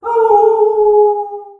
Tono de llamada Aullido del lobo